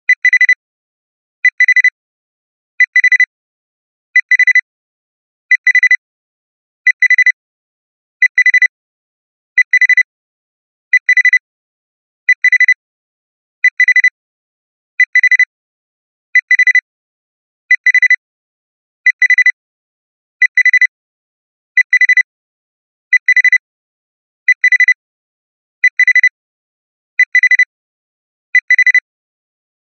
シンプルで洗練された単音メロディです。